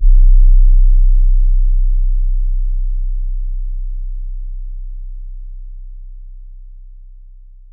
kits/TM88/808s/Que8082_YC.wav at main